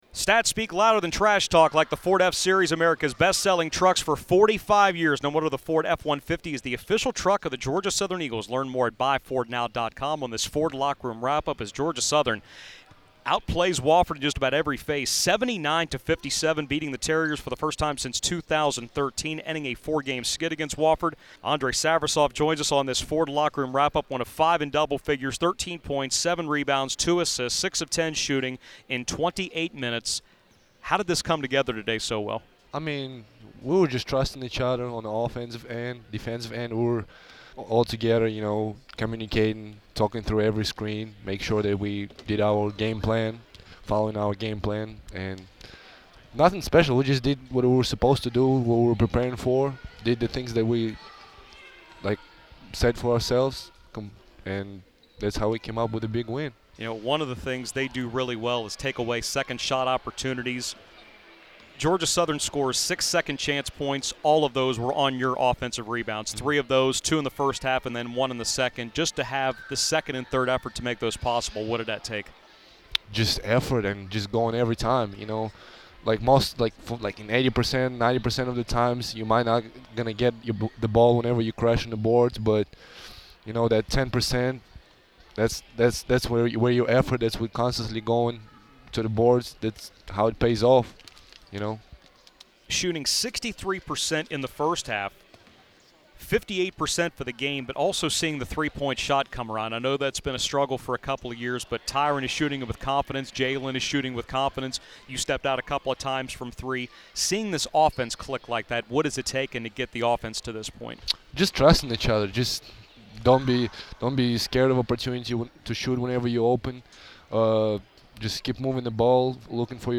Postgame radio interviews